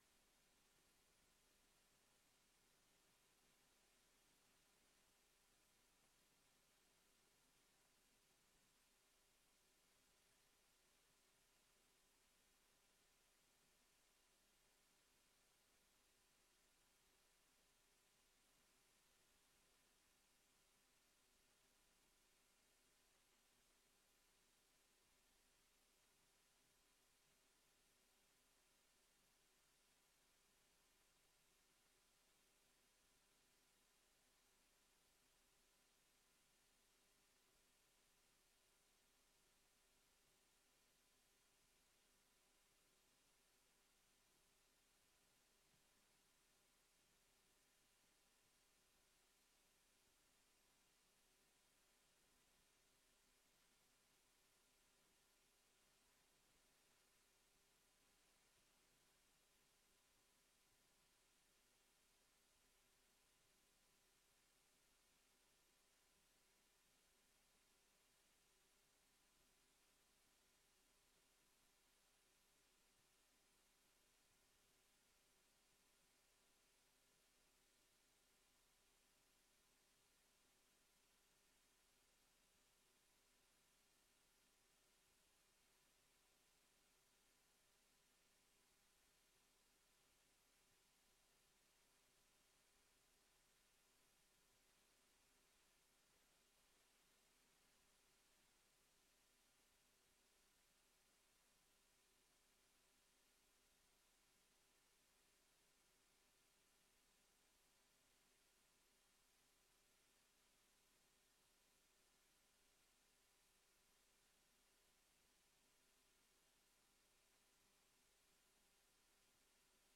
Locatie: Raadzaal Voorzitter: Victor Molkenboer